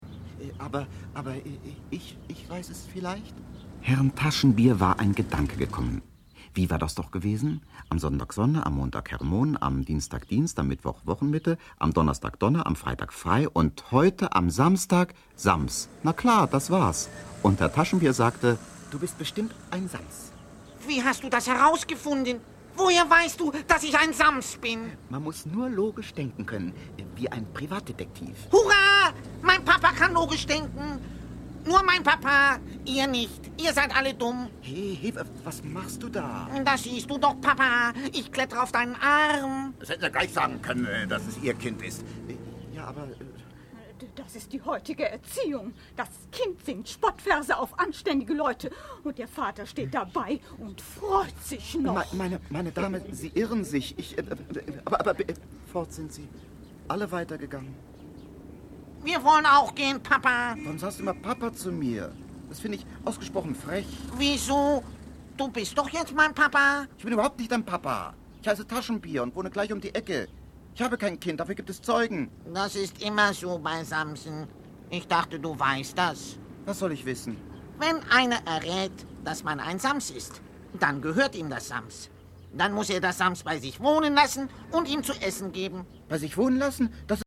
Hörbuch: Das Sams 1.